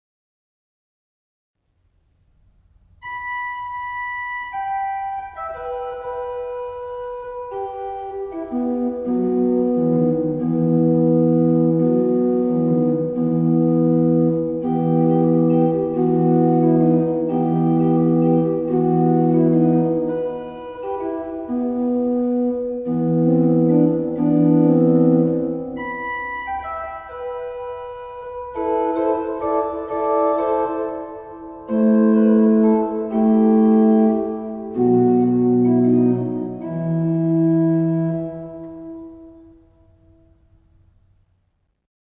Orgelvorspiele
Hier finden Sie einige Orgelvorspiele zu Liedern aus dem Gotteslob.
gg_867_ich_sing_dir_ein_lied_orgelvorspiel.mp3